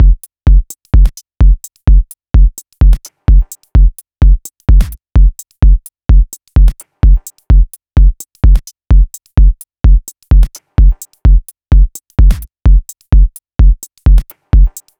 • Rhythm Electro Drum Machine House Minimal - Fm - 128.wav
Rhythm_Electro_Drum_Machine_House_Minimal_-_Fm_-_128_Vin.wav